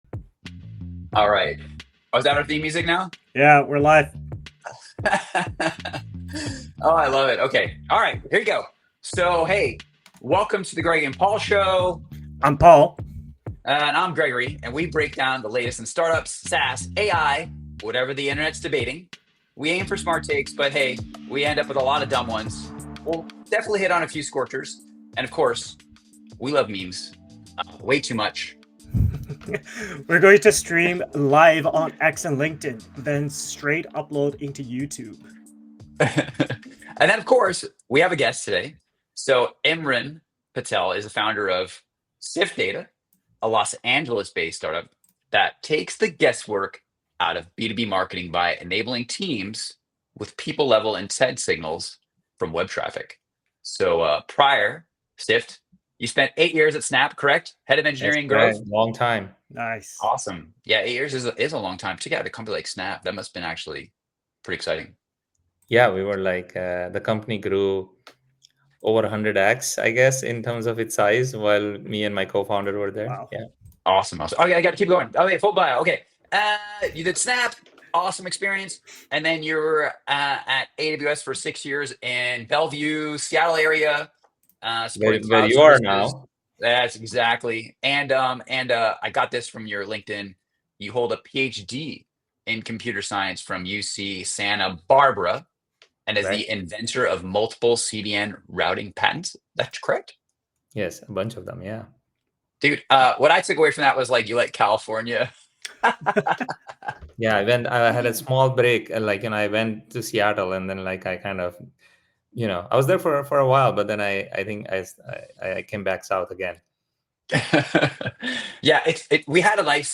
We stream live on X and LinkedIn at Noon Pacific every Friday, then straight on to YouTube.